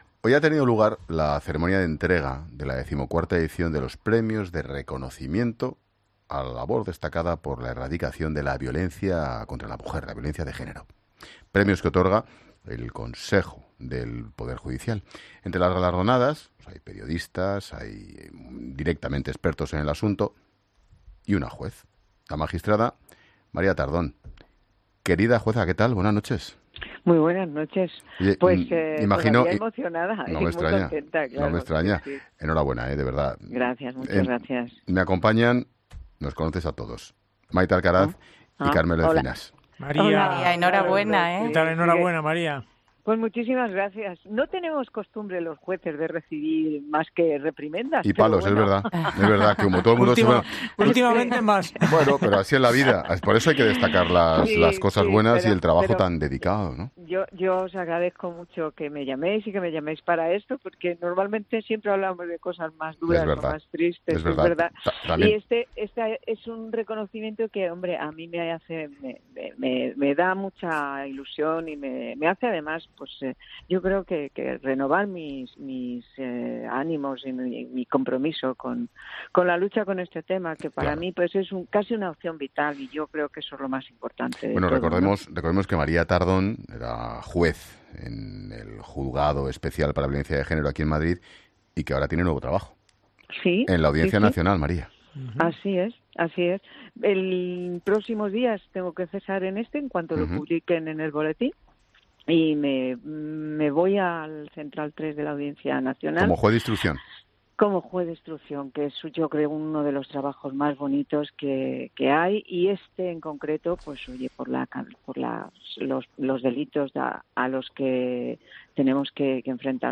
Ahora, este galardón "es un reconocimiento que hace renovar mis ánimos y mi compromiso en la lucha con este tema", un tema que para Tardón es "casi una opción vital", ha apuntado en los micrófonos de COPE